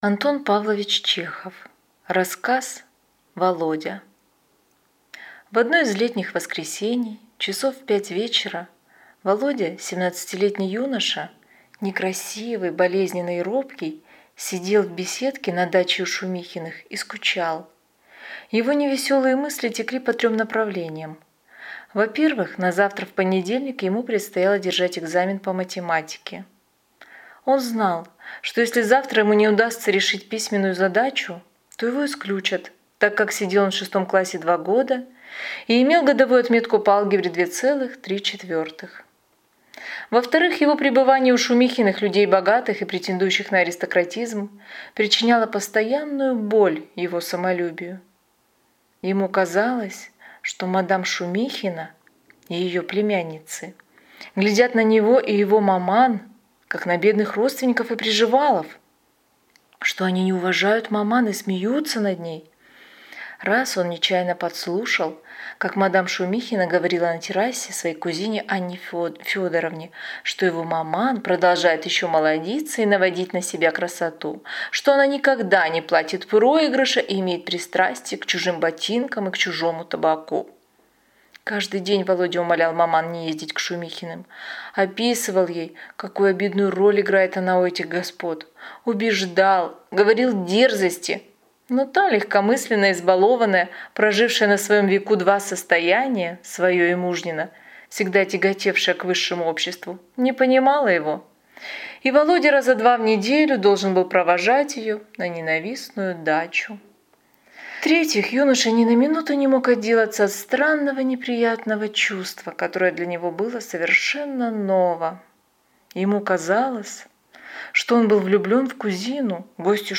Аудиокнига Володя | Библиотека аудиокниг